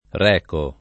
recare v.; reco [ r $ ko ], rechi